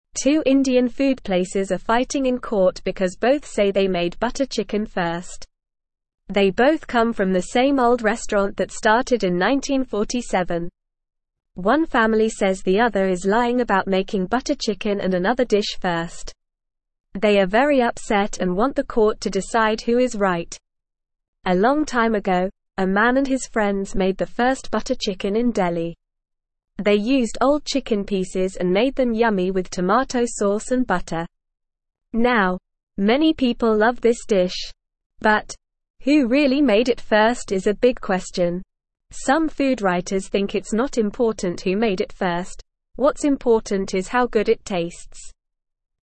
Normal
English-Newsroom-Beginner-NORMAL-Reading-Butter-Chicken-Battle-Who-Made-It-First.mp3